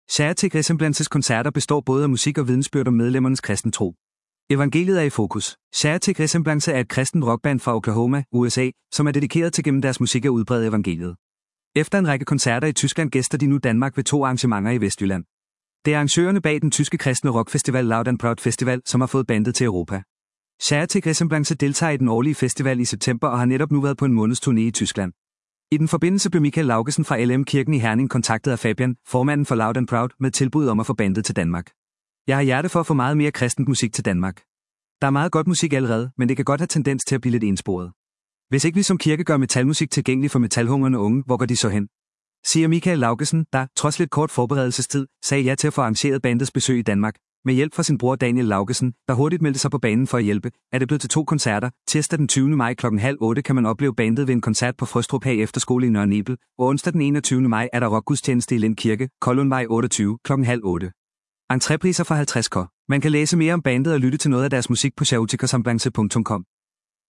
kristent rockband